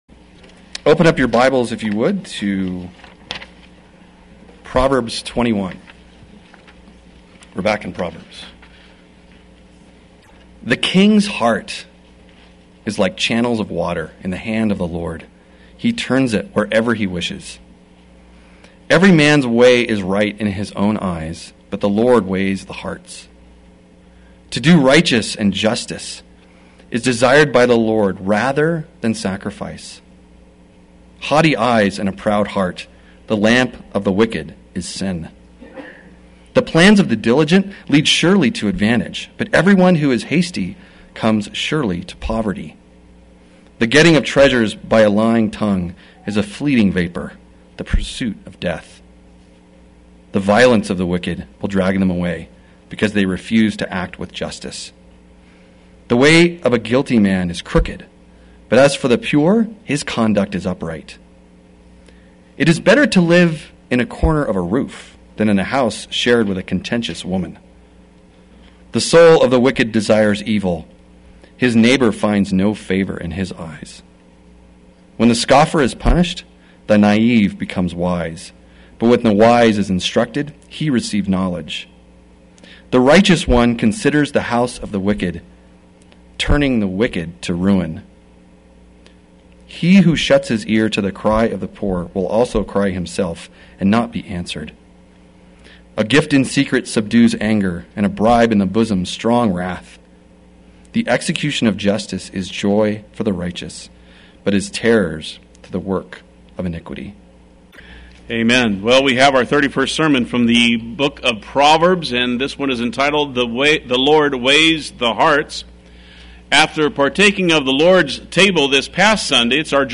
Play Sermon Get HCF Teaching Automatically.
The Lord Weighs the Hearts Sunday Worship